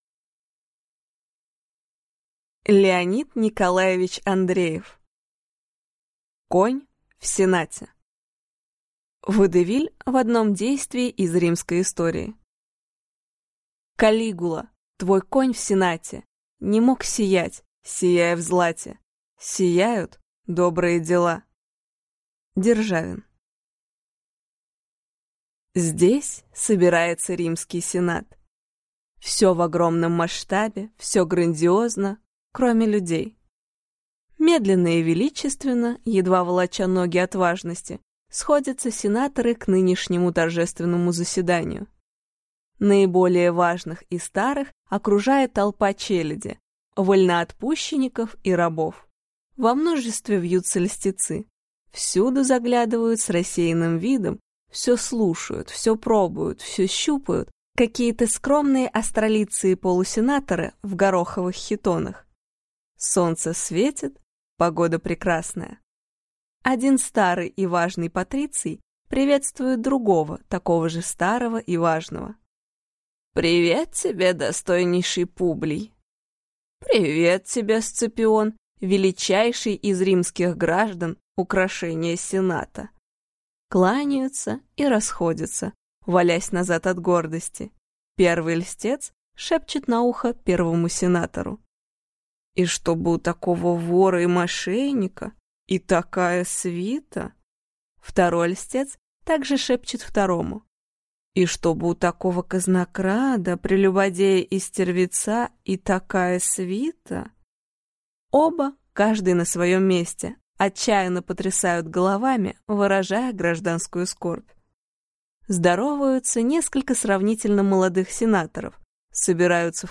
Аудиокнига Конь в сенате | Библиотека аудиокниг
Прослушать и бесплатно скачать фрагмент аудиокниги